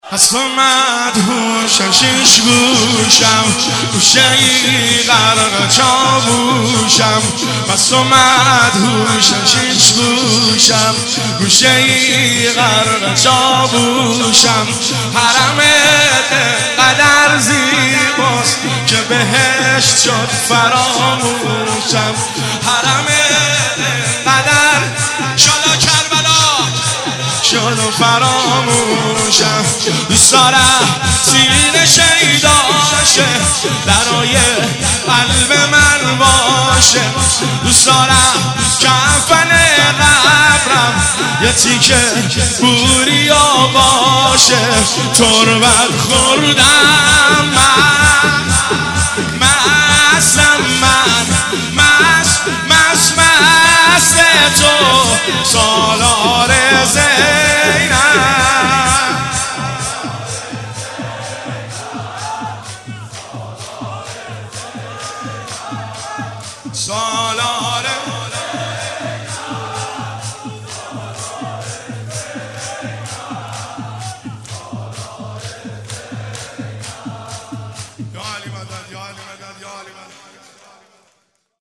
مداحی مس و مدهوش شش گوشم